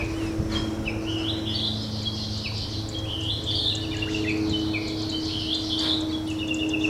録音はジュウイチとオオムシクイ、ウグイスの合唱（約7秒間）